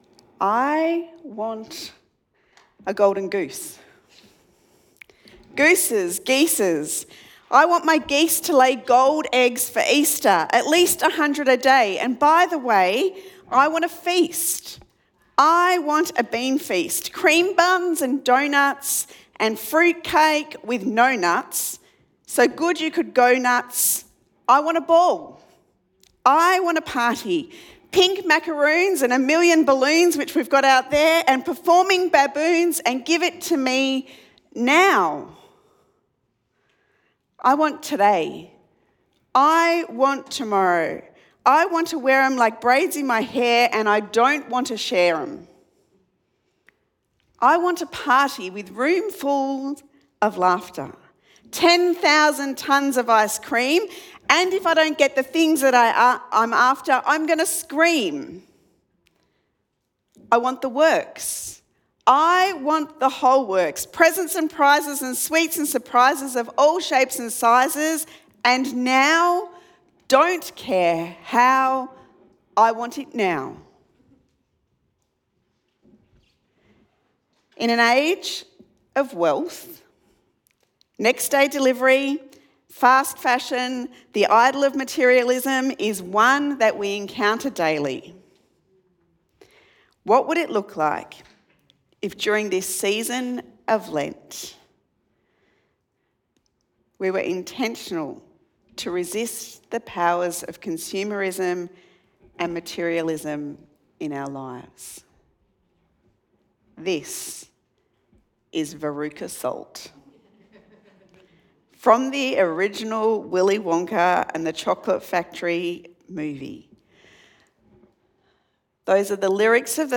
Sermon Podcasts Wisdom in the Desert